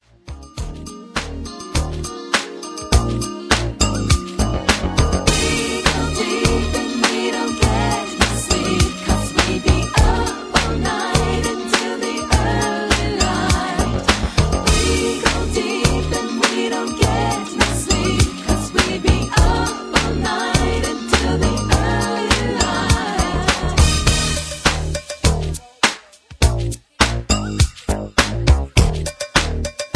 Key-Ebm) Karaoke MP3 Backing Tracks
Just Plain & Simply "GREAT MUSIC" (No Lyrics).